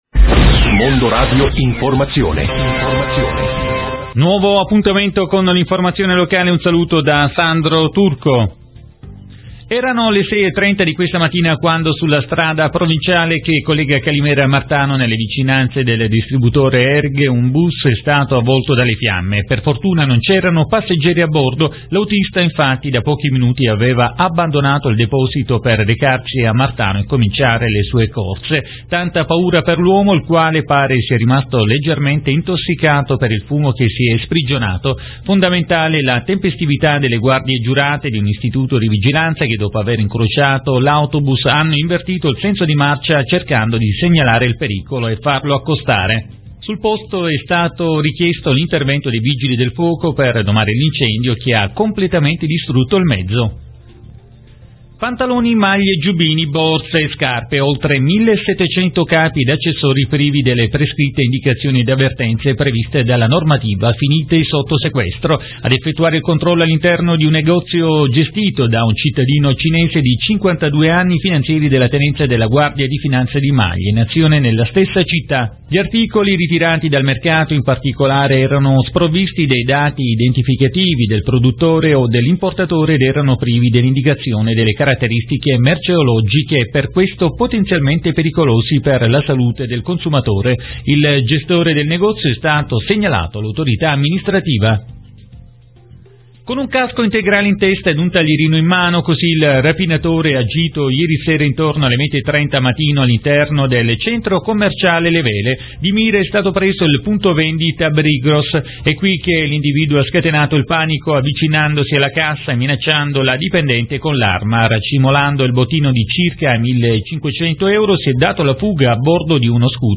Documenti allegati: Rassegna stampa_Visti da noi Gr Mondoradio